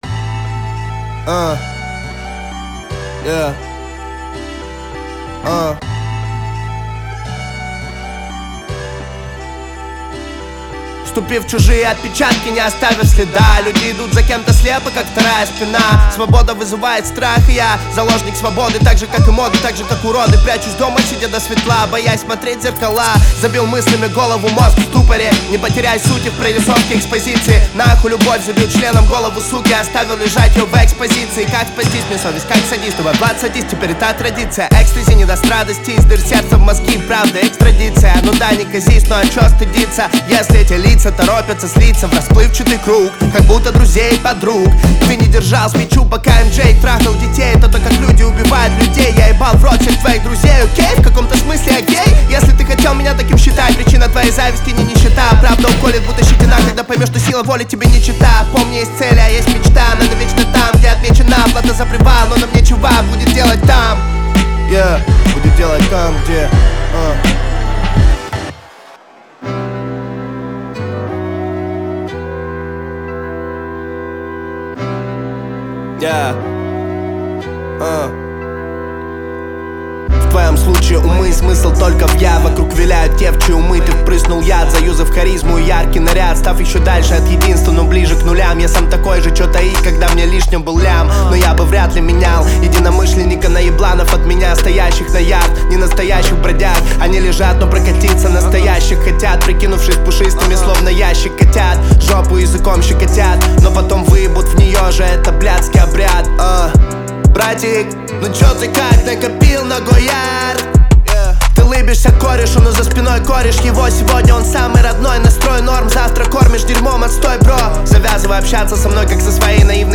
Категории: Русские песни, Рэп и хип-хоп.